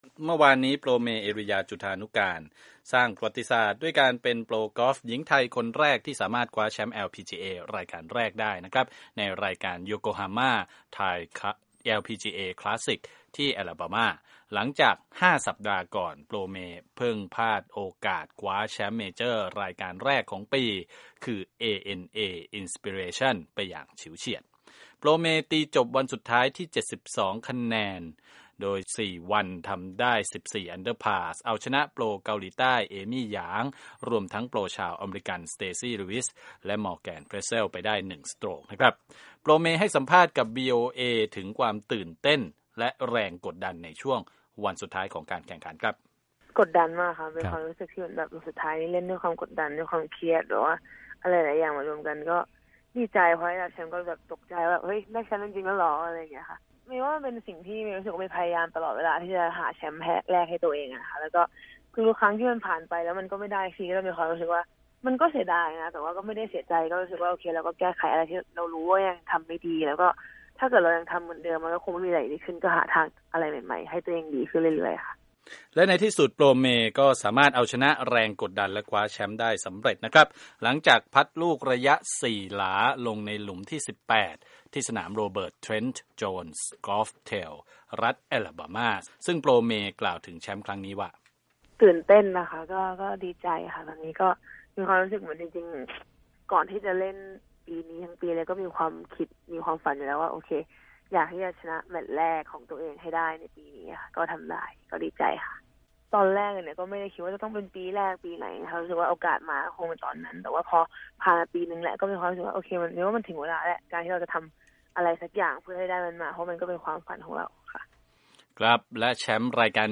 Interview Pro Ariya